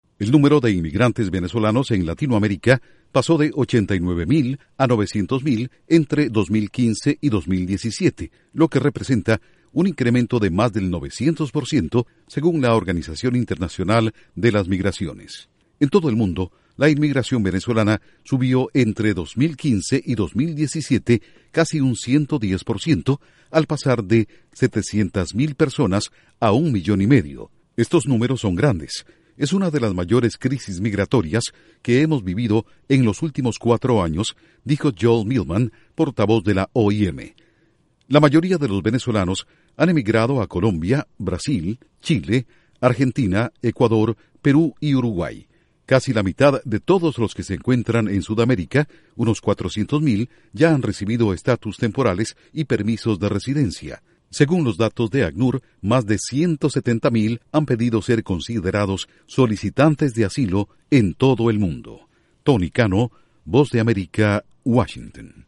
OIM: Sube un 900% el número de migrantes venezolanos en Latinoamérica. Miles piden asilo en todo el mundo. Informa desde la Voz de América en Washington